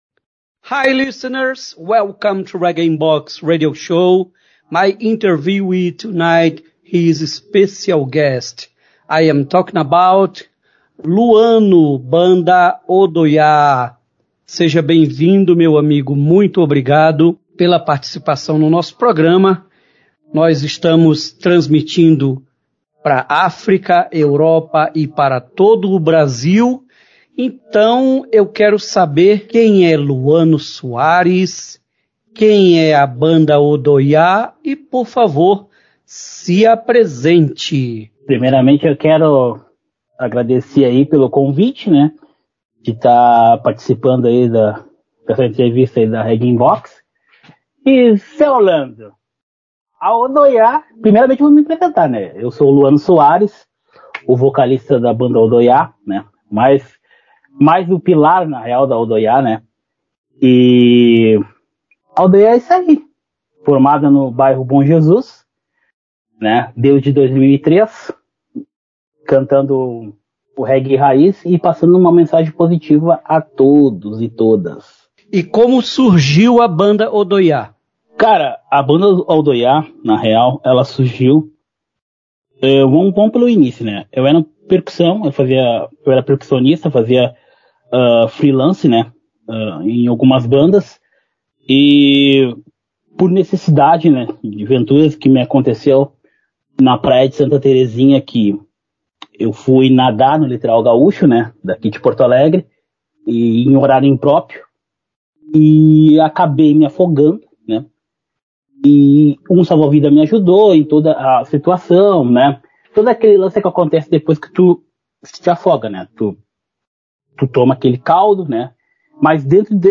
RIB Entrevista